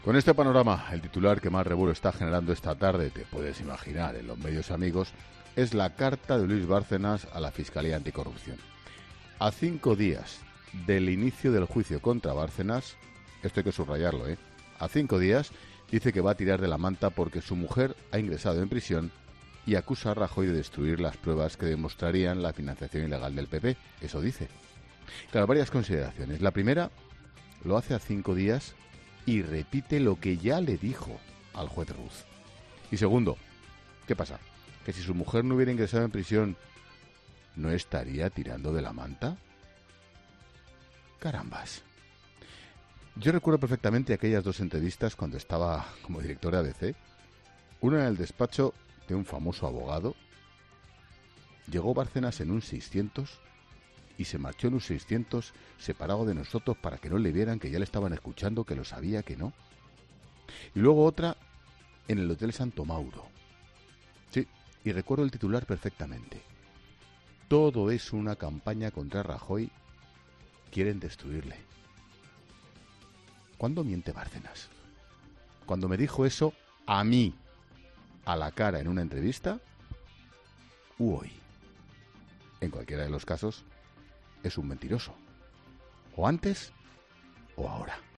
El presentador de 'La Linterna' hace memoria sobre sus encuentros con el extesorero del PP